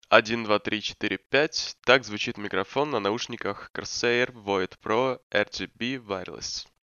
7.1 Качество микрофона
Поп фильтр идущий в комплекте, лучше надеть сразу.
Не хватает низких частот. Да и в целом качество средненькое.
Микрофон Corsair Void Pro RGB WIRELESS Gaming Headset:
corsair-void-pro-rgb-wireless-mikrofon.mp3